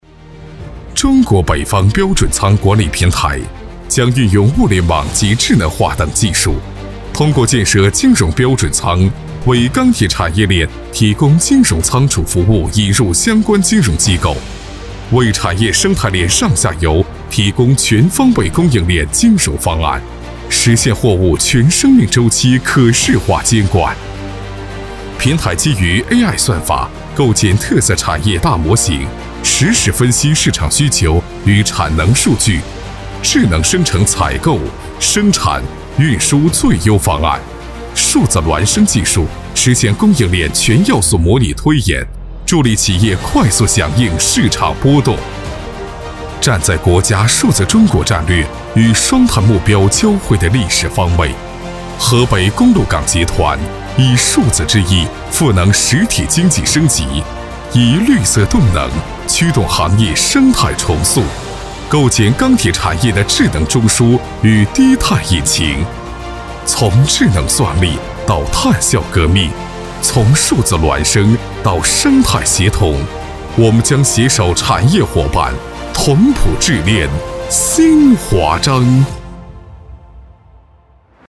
男国语217